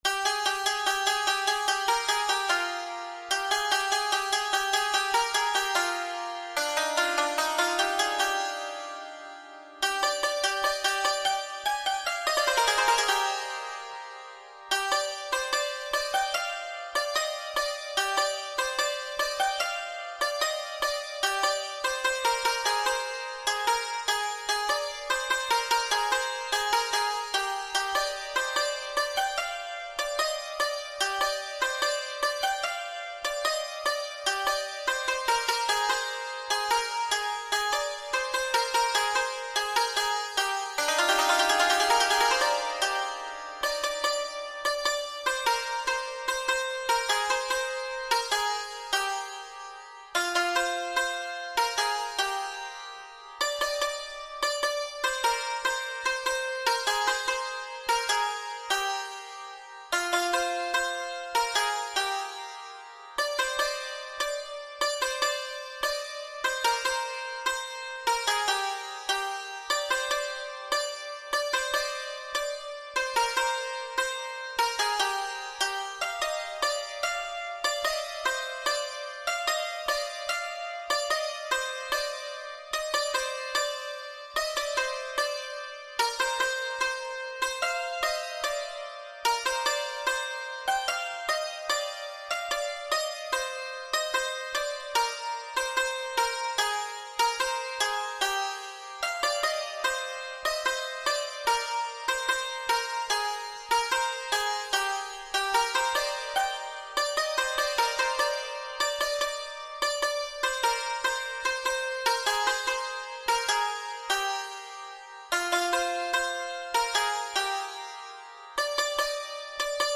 سطح : ساده